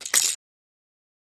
Baby Toy Rattle, Single Shake